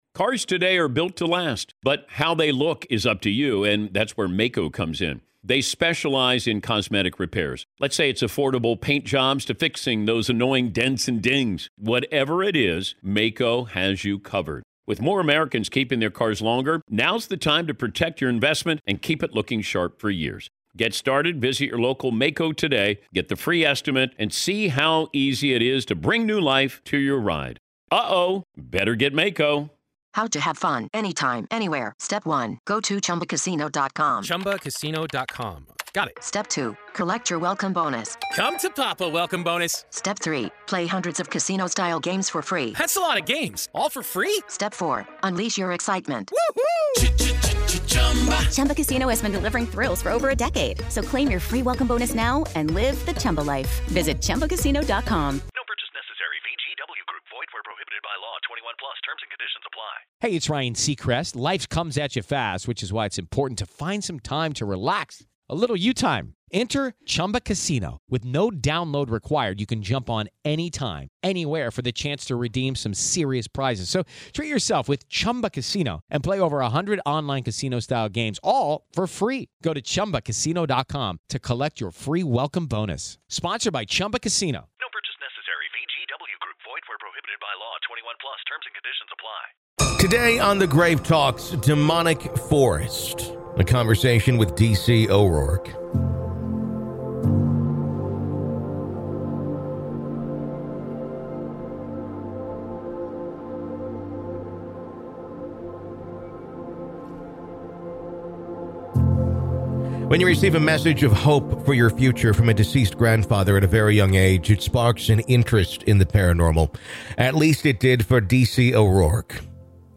In part two of our interview, available only to Grave Keepers , we discuss: